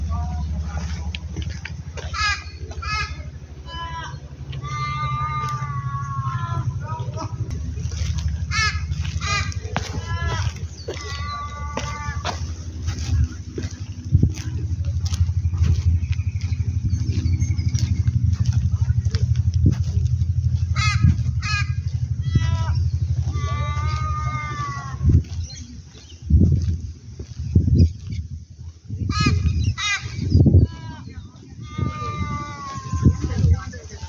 Corvus coronoides
Nome em Inglês: Australian Raven
Localidade ou área protegida: Lamington National Park
Condição: Selvagem
Certeza: Gravado Vocal
australian-raven-royal-park.mp3